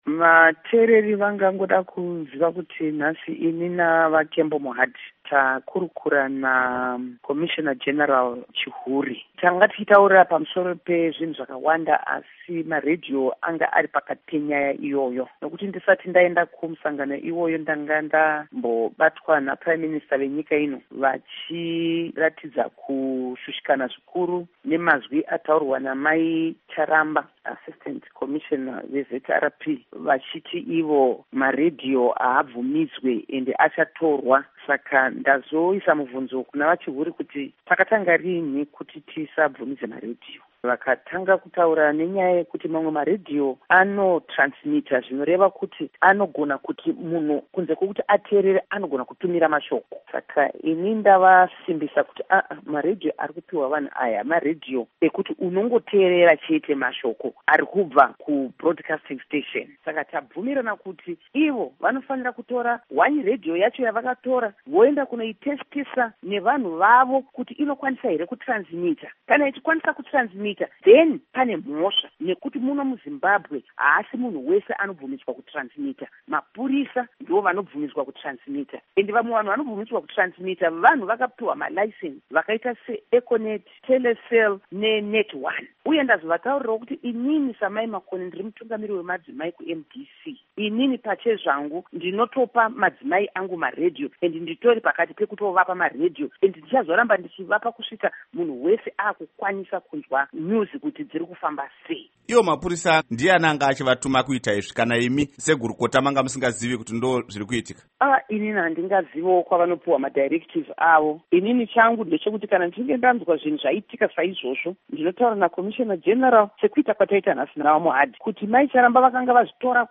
Hurukuro naAmai Theresa Makone